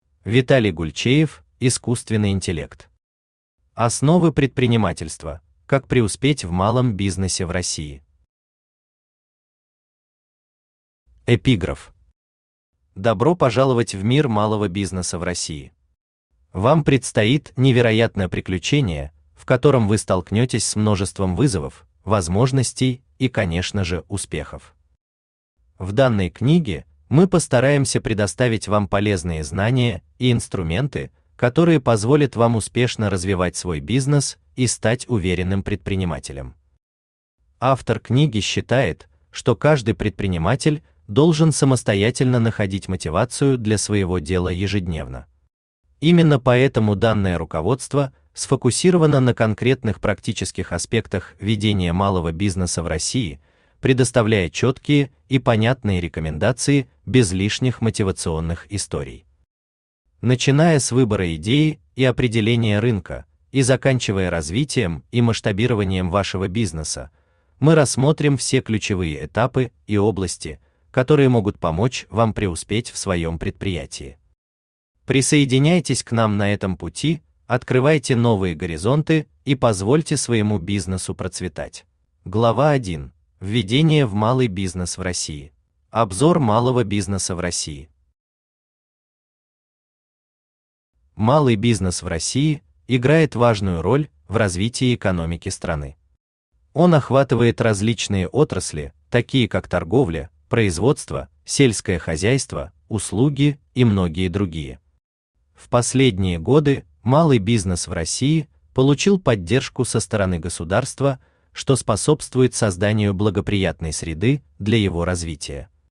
Аудиокнига Основы предпринимательства: как преуспеть в малом бизнесе в России | Библиотека аудиокниг
Aудиокнига Основы предпринимательства: как преуспеть в малом бизнесе в России Автор Виталий Александрович Гульчеев Читает аудиокнигу Авточтец ЛитРес.